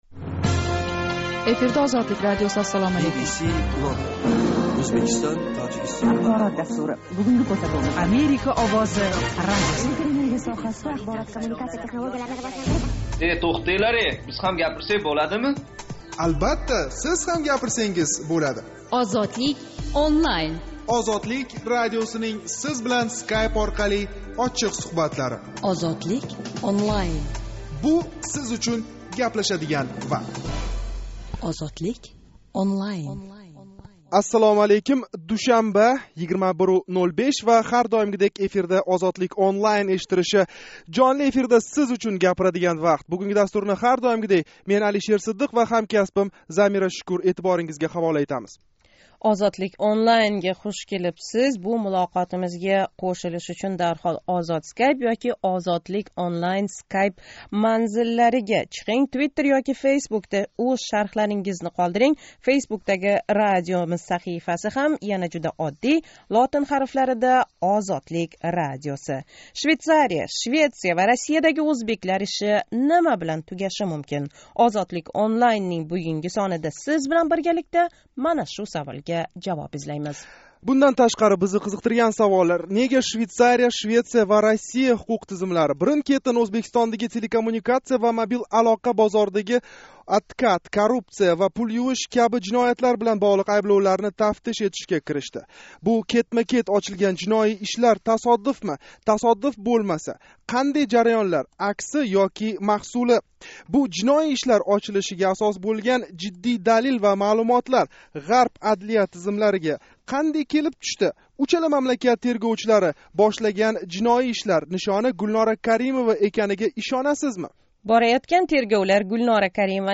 Ozodlik ONLINE жонли интерактив мулоқотининг Тошкент вақти билан 21:05 да бошланган янги сонида ана шу савол атрофида мулоҳаза юритдик.